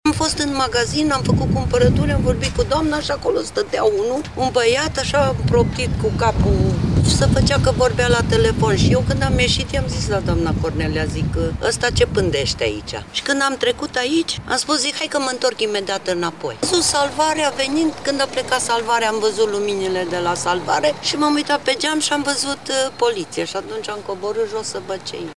vox-martor.mp3